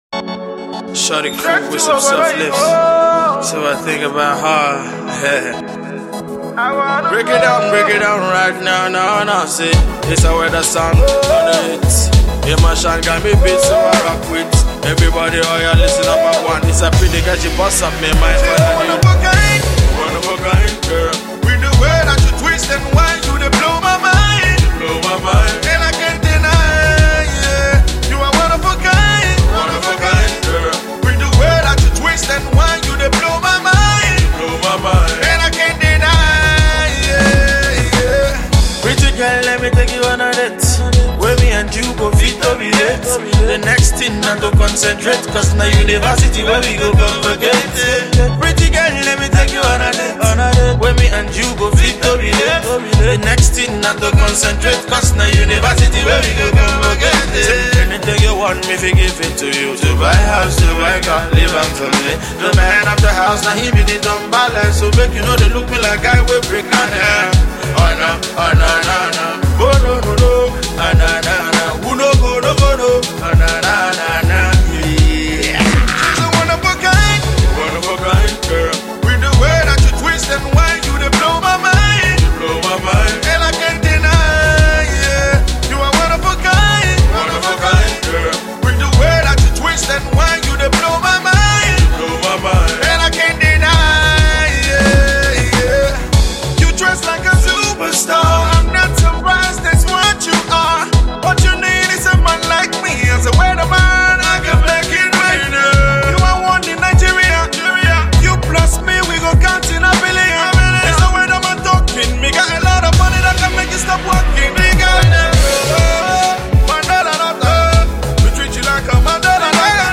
His music can be described as Afro reggae hip hop.